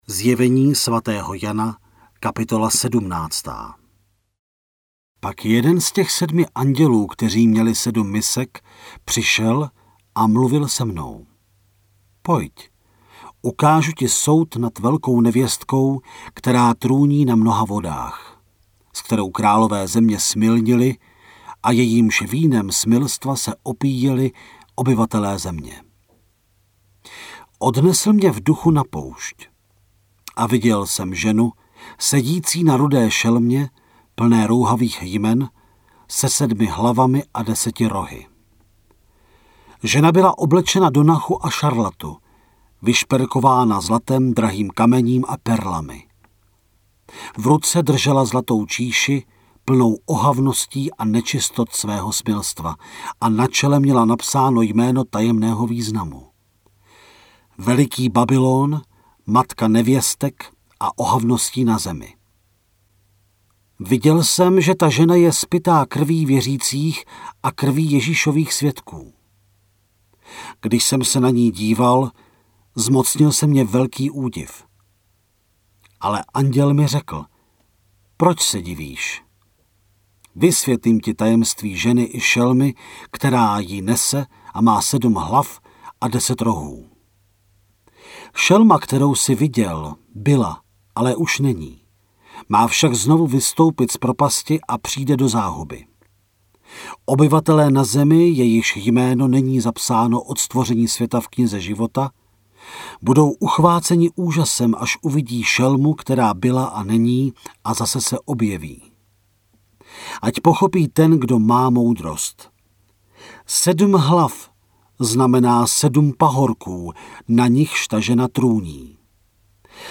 Načtená kniha je rozdělená na 22 částí, které odpovídají dělení kapitol.
Stahujte celé zde (75MB):  ZJEVENÍ SVATÉHO JANA – audiokniha, Studio Vox 2018